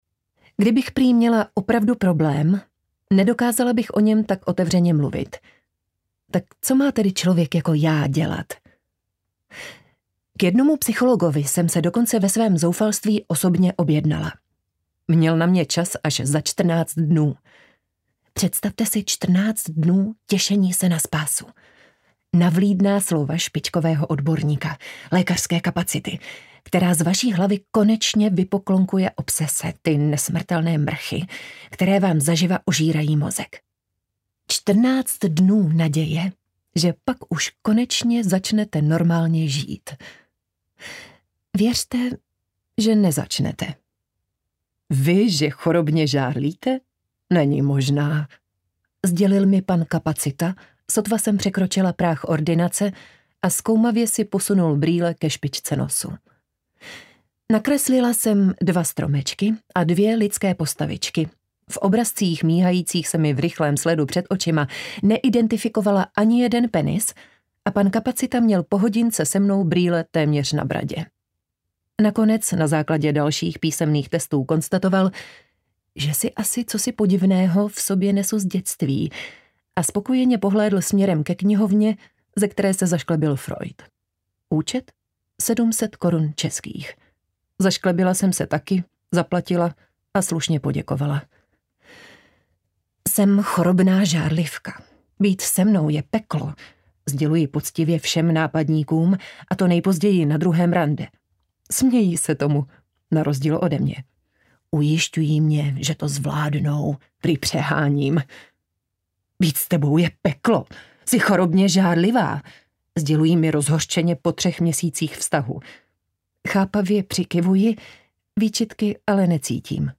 Žárlivka audiokniha
Ukázka z knihy
zarlivka-audiokniha